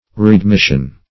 readmission - definition of readmission - synonyms, pronunciation, spelling from Free Dictionary
Readmission \Re`ad*mis"sion\ (r[=e]`[a^]d*m[i^]sh"[u^]n), n.